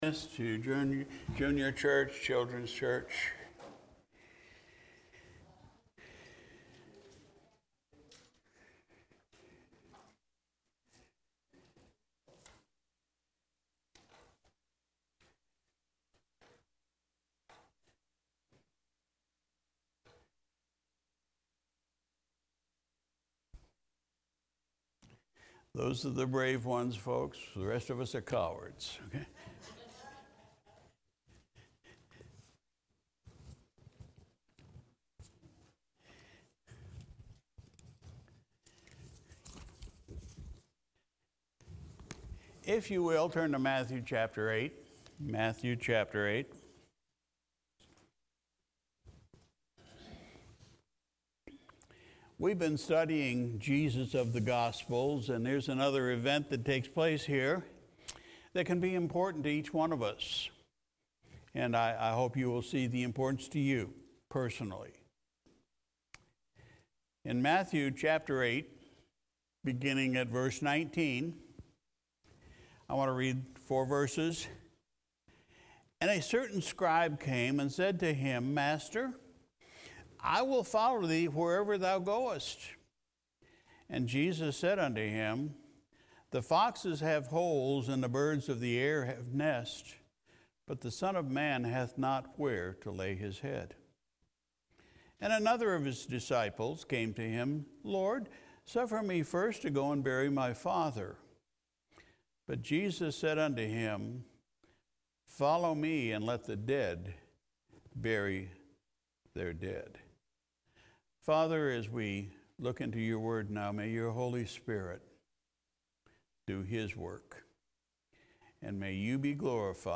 July 4, 2021 Sunday Morning Service Pastor’s Message: “The Cost”